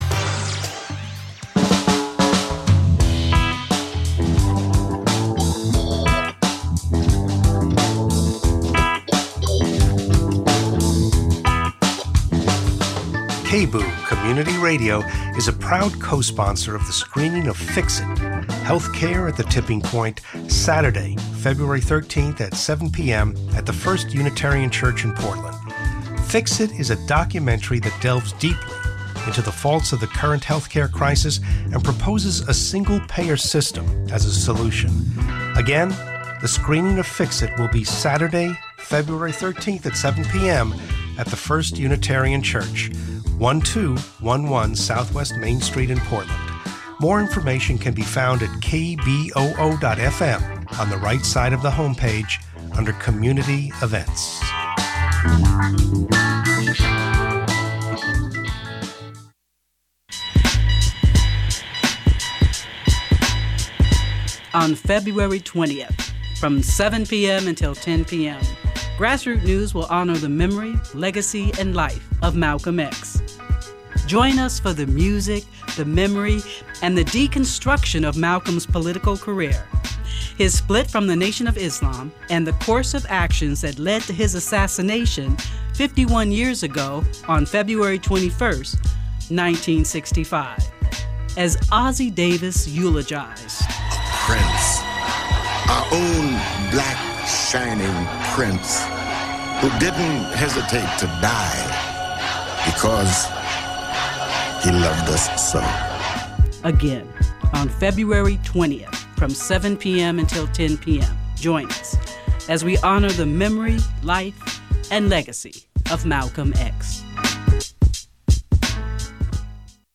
young artists performing spoken word, rap, songs